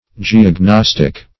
Search Result for " geognostic" : The Collaborative International Dictionary of English v.0.48: Geognostic \Ge`og*nos"tic\, Geognostical \Ge`og*nos"tic*al\, a. [Cf. F. g['e]ognostique.] Of or pertaining to geognosy, or to a knowledge of the structure of the earth; geological.